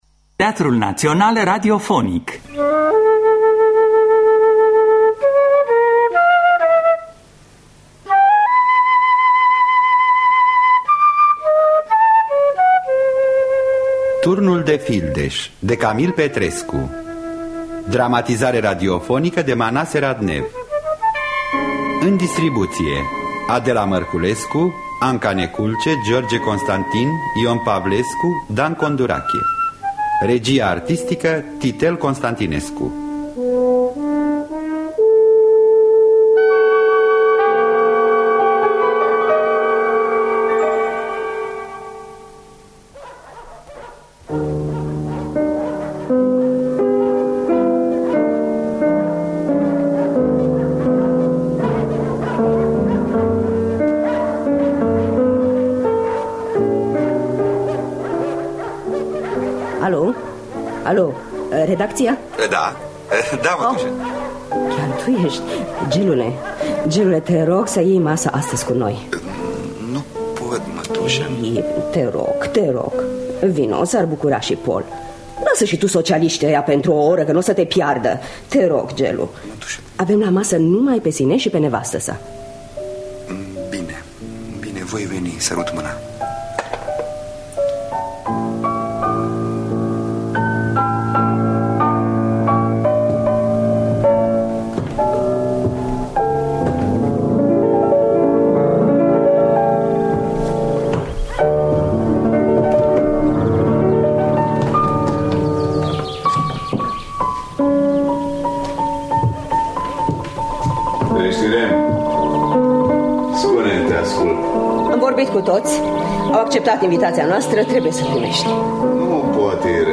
Dramatizarea radiofonică de Manase Radnev.